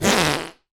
Suicide Pop Sound Effect
Download a high-quality suicide pop sound effect.
suicide-pop-1.mp3